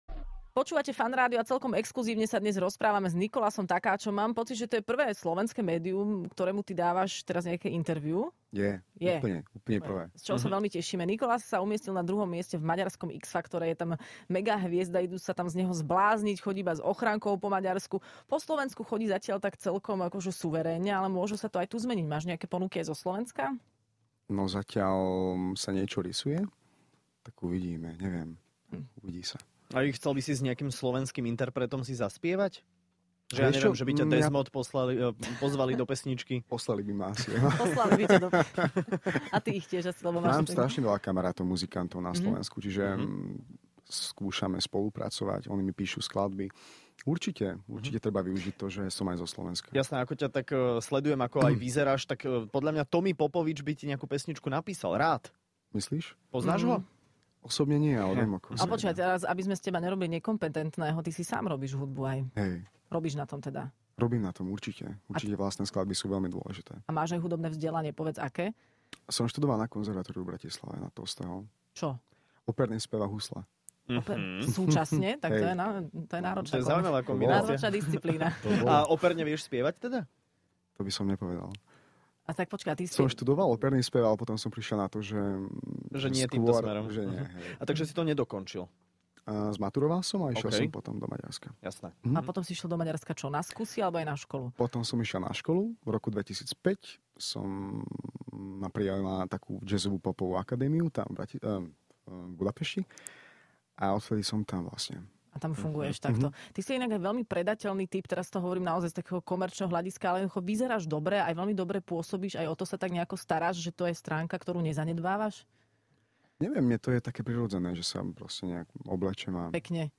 Hosť